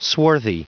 Prononciation du mot swarthy en anglais (fichier audio)
Prononciation du mot : swarthy